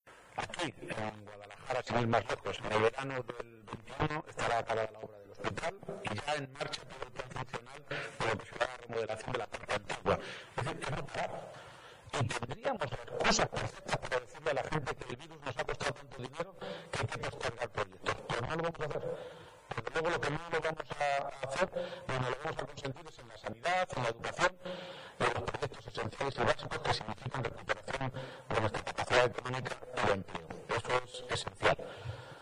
«En Guadalajara, en el verano de 2021, estará acabada la obra del Hospital y se pondrá en marcha todo el plan funcional de lo que será la remodelación de la parte antigua», ha detallado durante una intervención en Sigüenza el jefe del Ejecutivo autonómico.